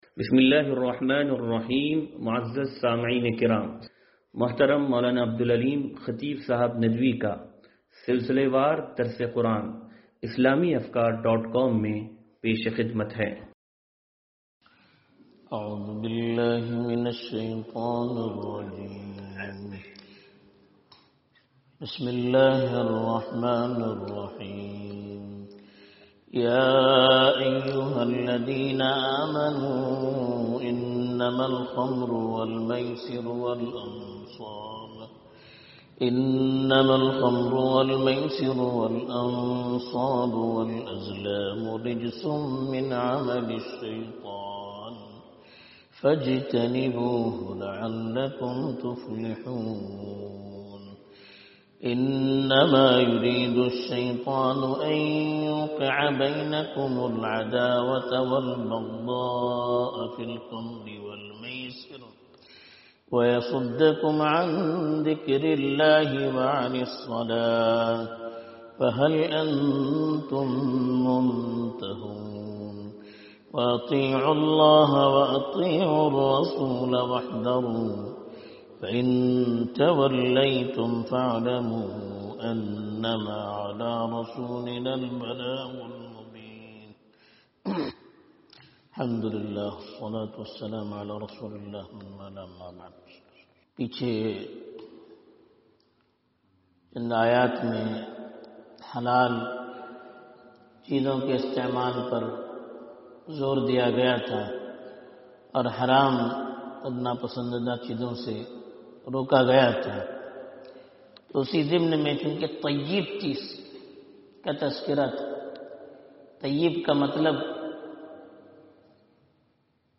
درس قرآن نمبر 0481